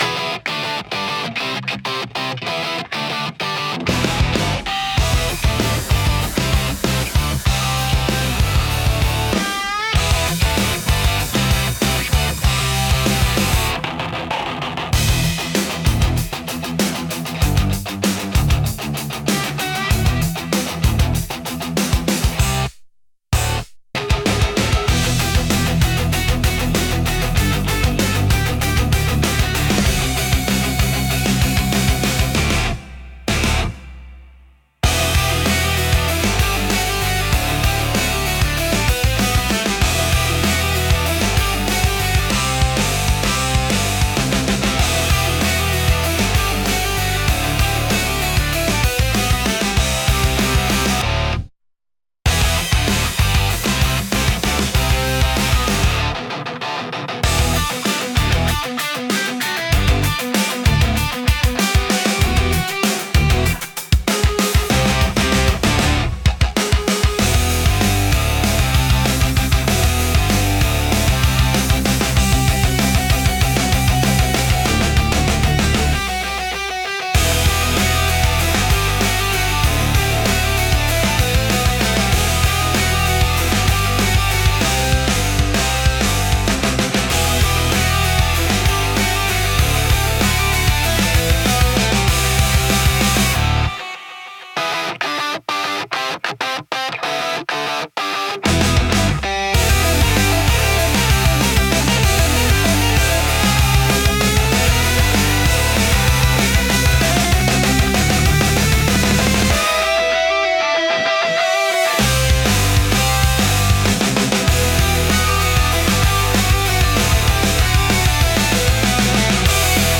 Urban Rock Energy